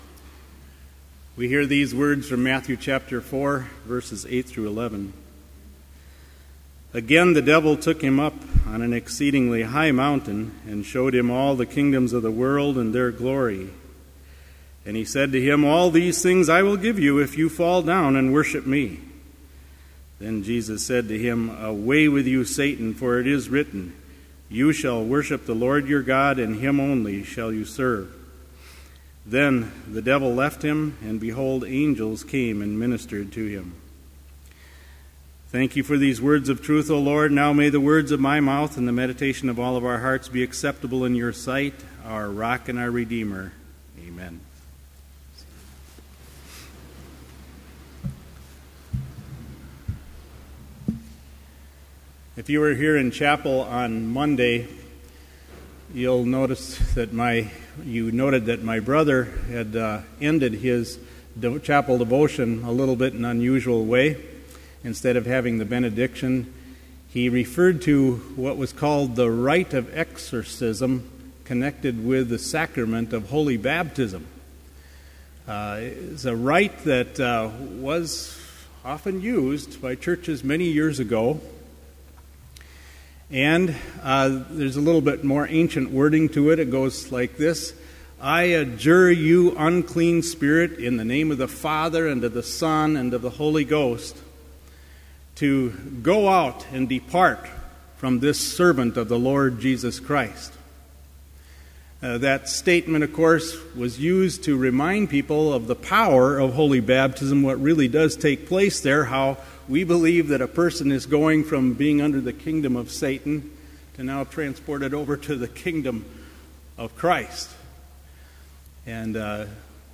Complete service audio for Chapel - March 7, 2013